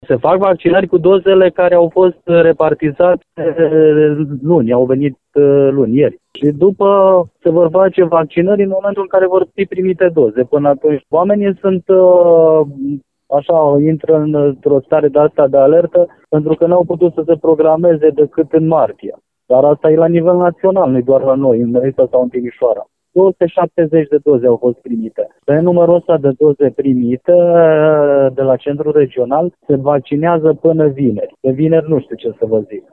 La rândul său, directorul Direcției de Sănătate Publică din județul Caraș-Severin, Dan Miloş spune că la Resiţa se vaccinează în limita celor 270 de doze primite.